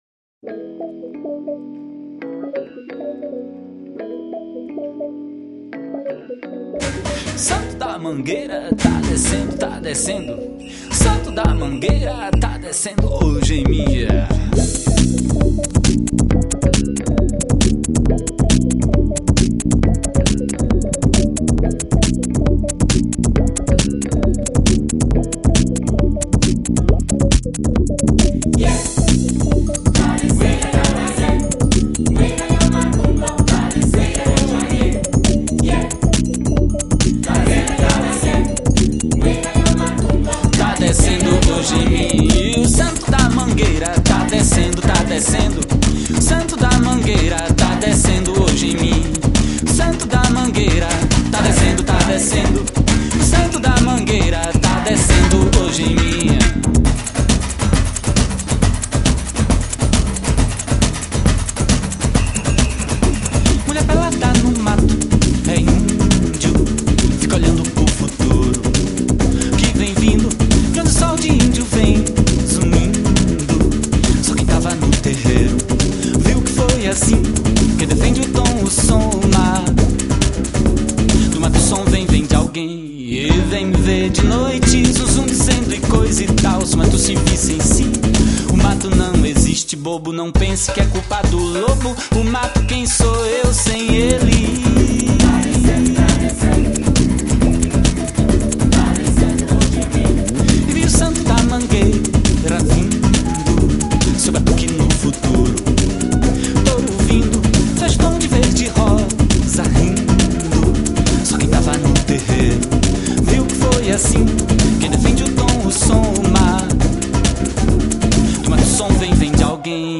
Genre : MPB